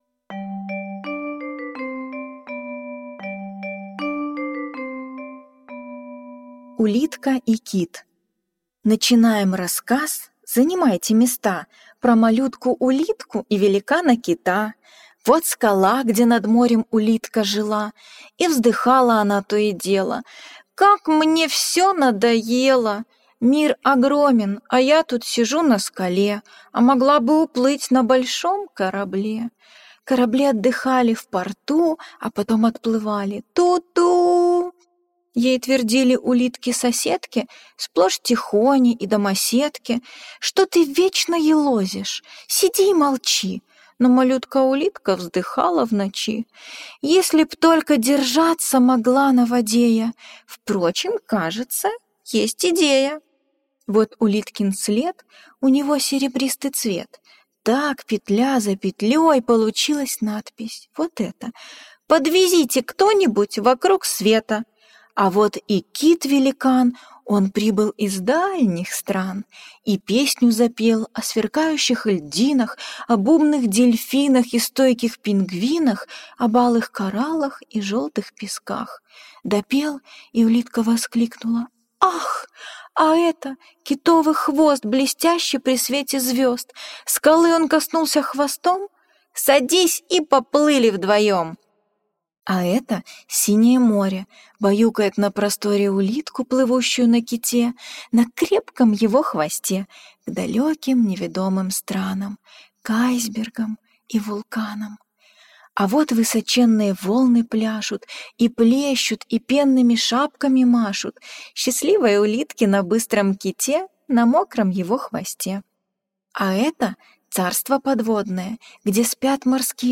Улитка и кит - аудиосказка Джулии Дональдсон - слушать онлайн